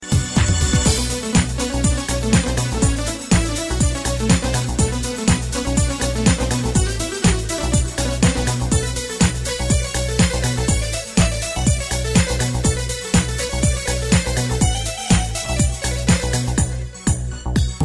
Electronica
house minimal